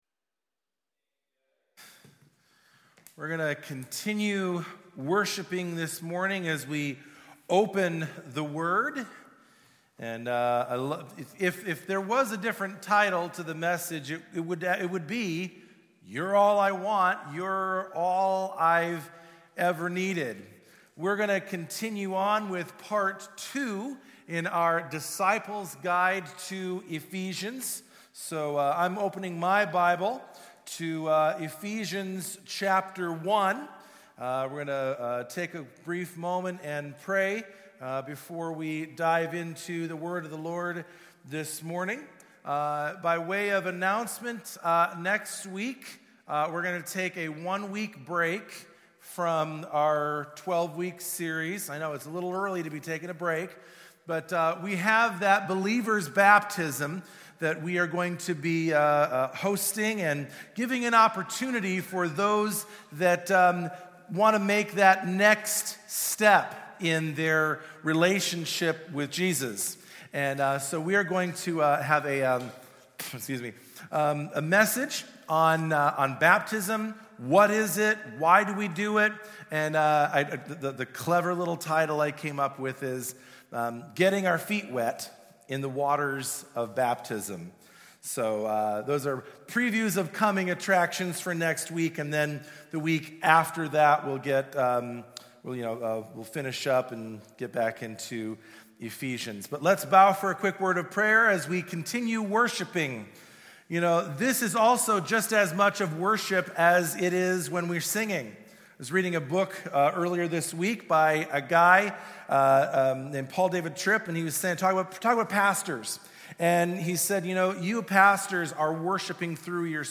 This Weeks Sermon